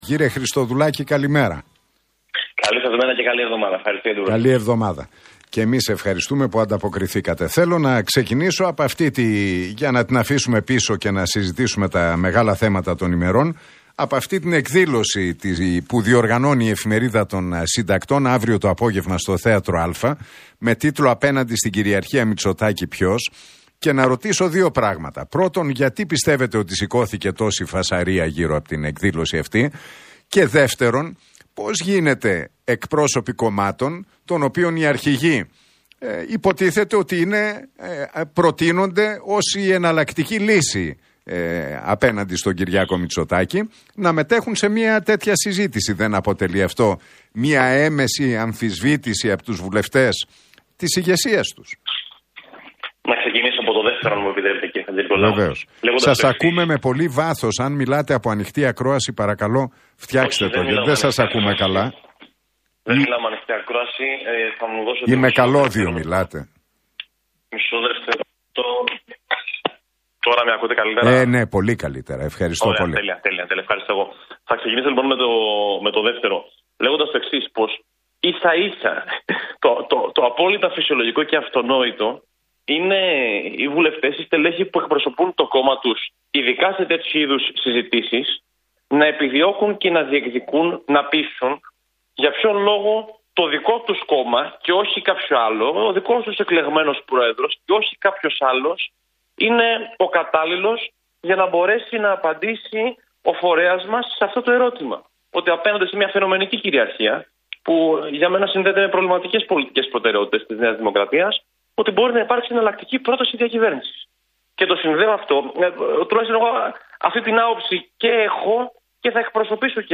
Χριστοδουλάκης στον Realfm 97,8: Δεν κατάλαβα γιατί σηκώθηκε τέτοια φασαρία – Τι είπε για την αυριανή εκδήλωση με Τεμπονέρα- Αχτσιόγλου